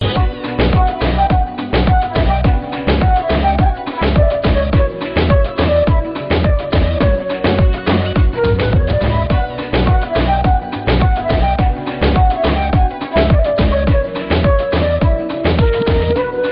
Category: Flute Ringtones